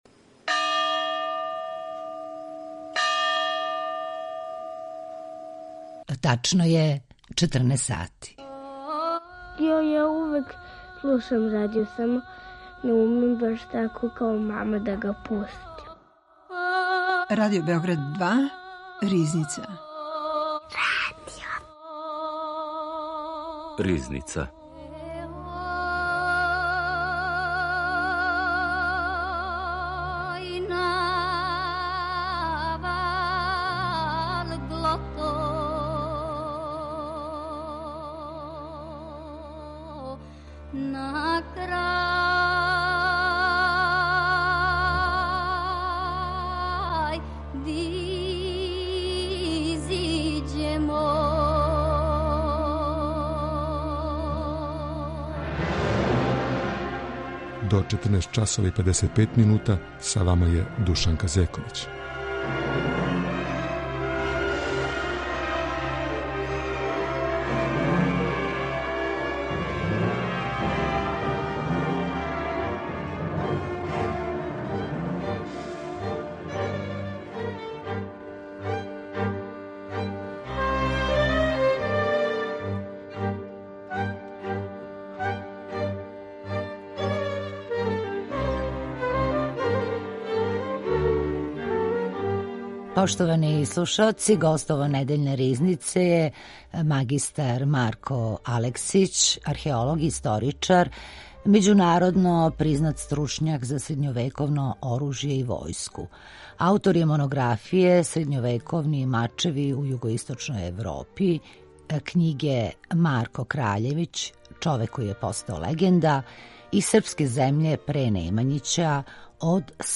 археолог и историчар